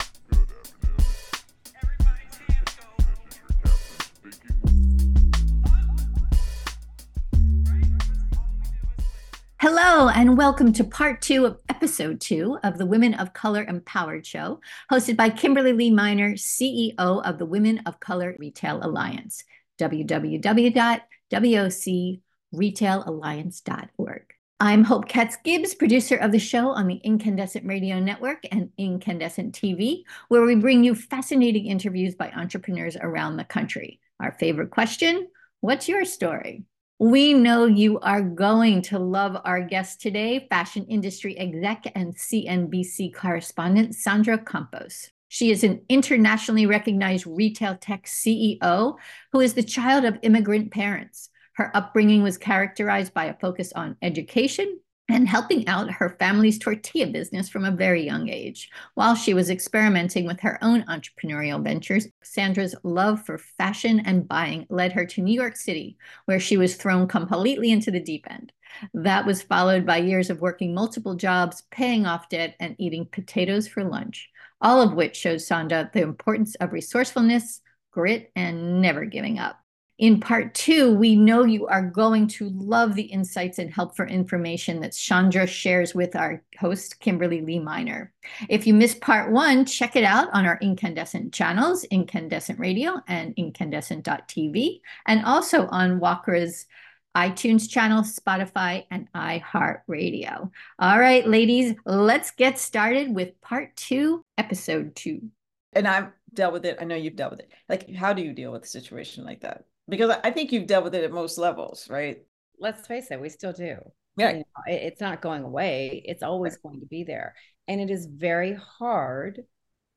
An interview with Fashion Industry Exec and CNBC correspondent